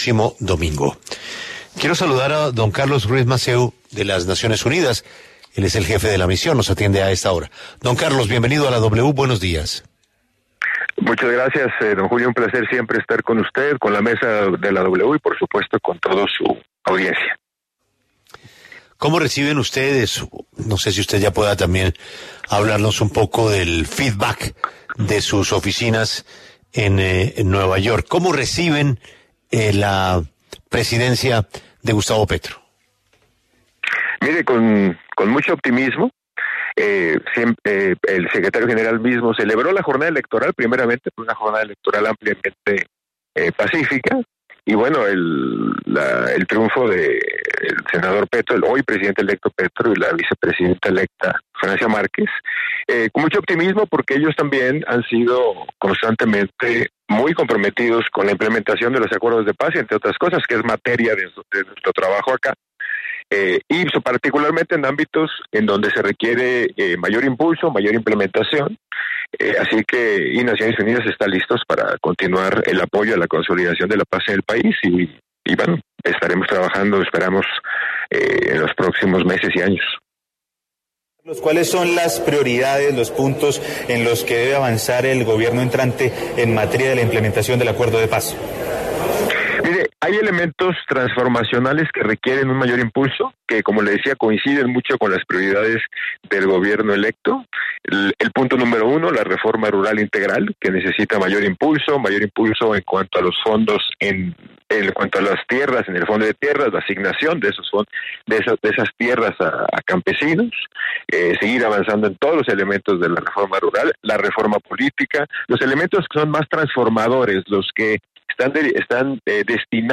En entrevista con La W, el jefe de la Misión de Verificación de Naciones Unidas para el Acuerdo de Paz, Carlos Ruiz Massieu, se refirió a la victoria del presidente electo Gustavo Petro y señaló que su gobierno debe avanzar en puntos del Acuerdo de Paz que aún presentan considerables rezagos como la reforma rural integral y la reforma política.